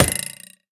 Minecraft Version Minecraft Version snapshot Latest Release | Latest Snapshot snapshot / assets / minecraft / sounds / item / trident / ground_impact1.ogg Compare With Compare With Latest Release | Latest Snapshot
ground_impact1.ogg